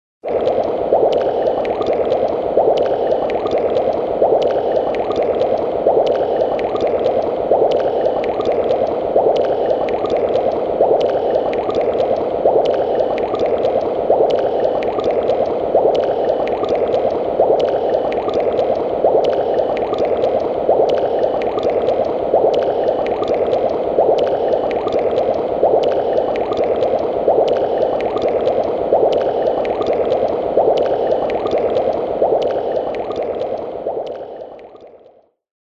Звуки ведьмы
Звук ведьминого зелья в котле